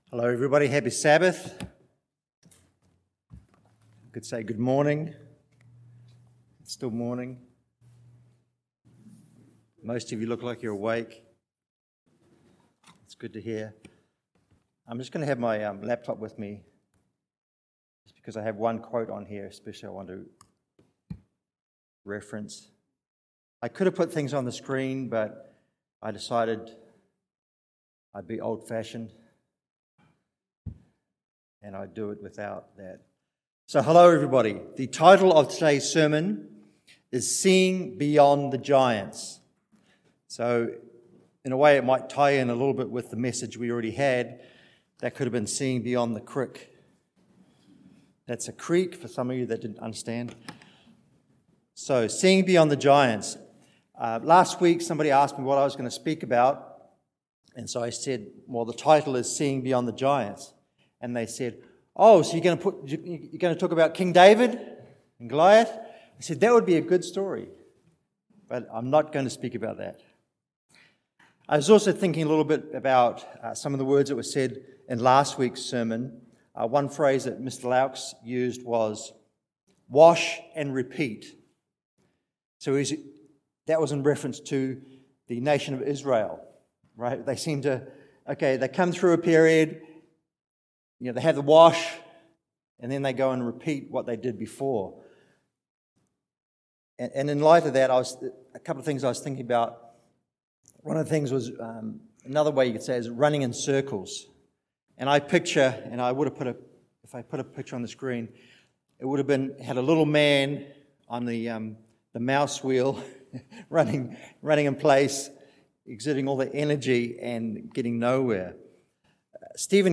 This sermon focuses upon one of the faithful men of encouragement who faced off against giants. Caleb was this mighty man of God who left us an example to draw strength from as we are faced with “Giant" obstacles in our own lives.